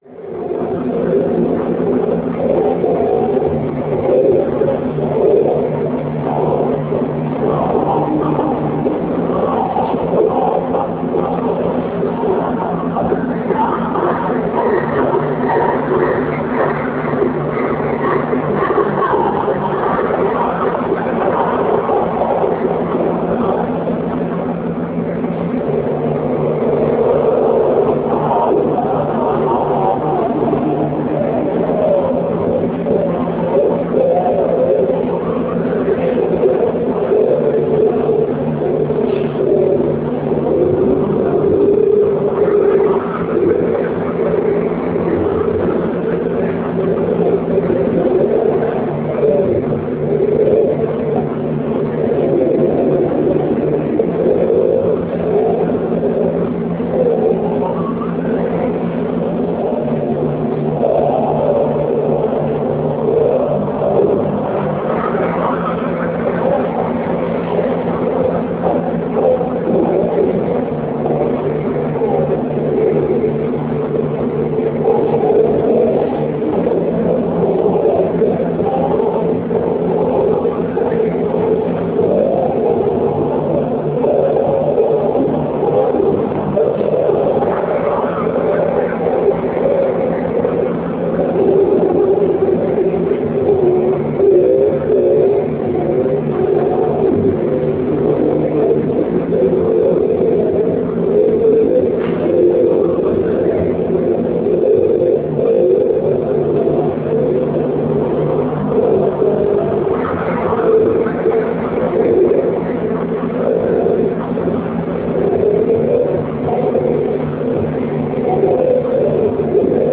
aufnahmen der gideon installation innerhalb der magazine show (can solo projects mit holger czukay) in berlin 1999